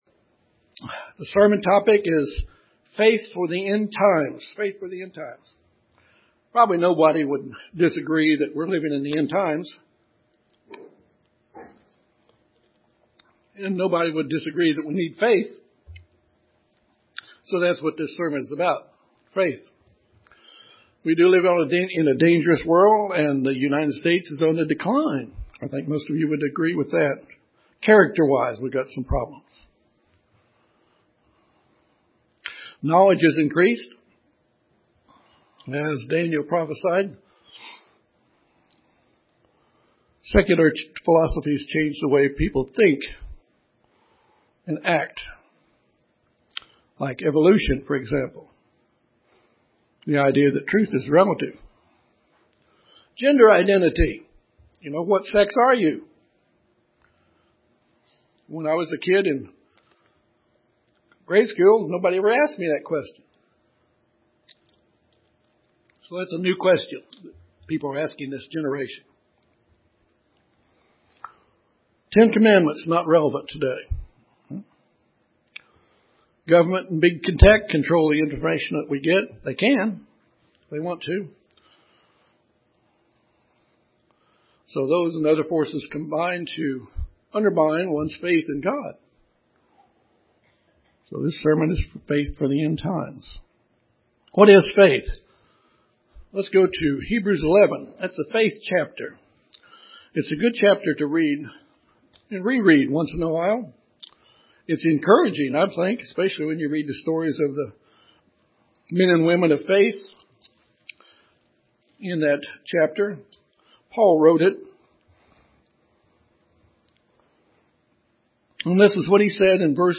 Most would agree that we are in the end-time and will need faith to endure this time. This sermon examines faith we need to endure in the end-time.